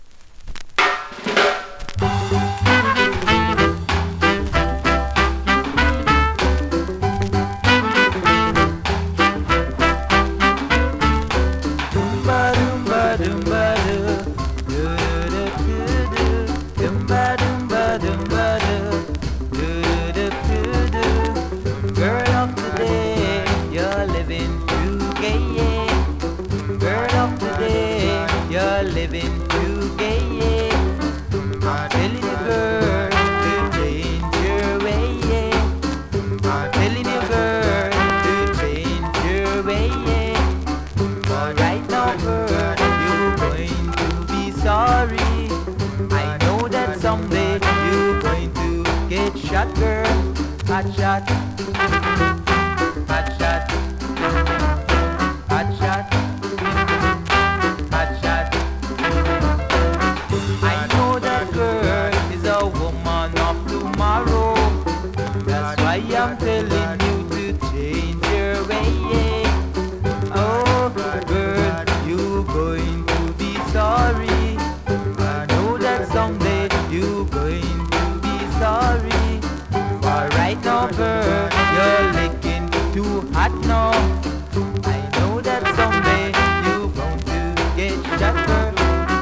両面とも数回針ブレますがプレイOKです)   コメントレアラベル!!レアROCKSTEADY!!
スリキズ、ノイズ比較的少なめで